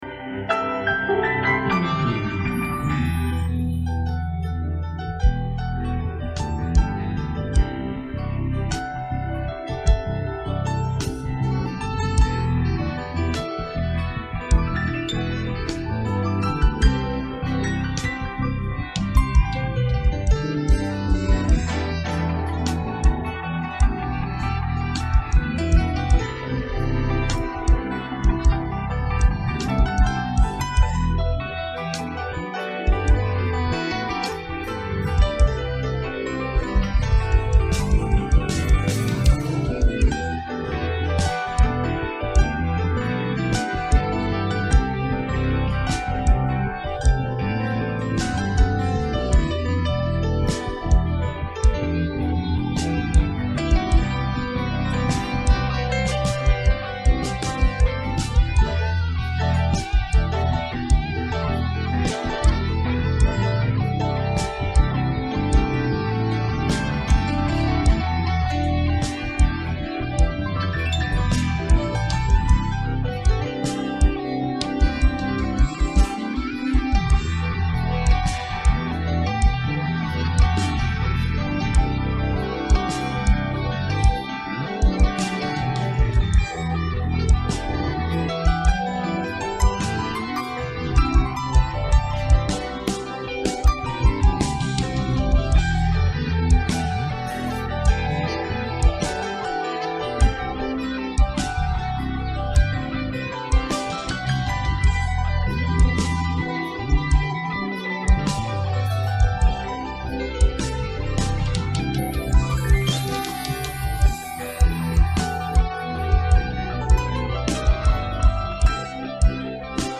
DOWNLOAD INSTRUMENTAL MP3